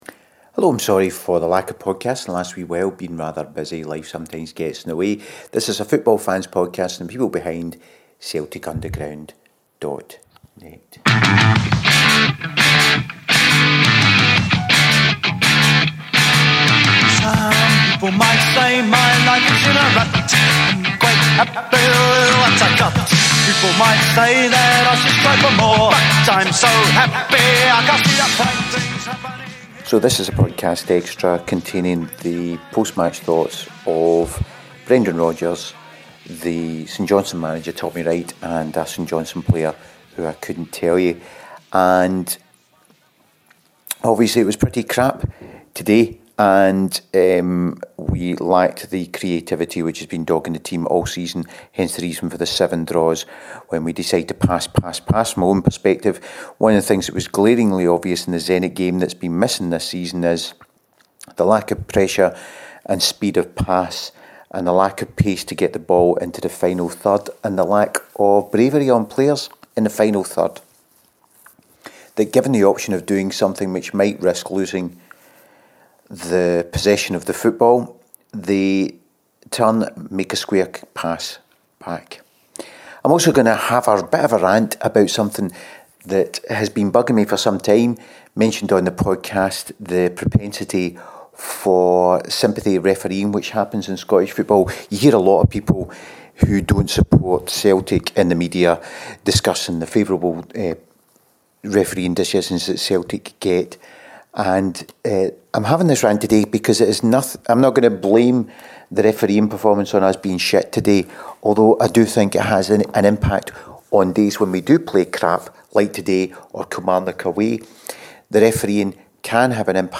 What I do do on the pod is have a little rant about sympathy reffing of wee teams in Scotland. We were crap today and the ref was not the reason however I am getting fed up with fouls being given but cards not and the consequences of this bad refereeing habit.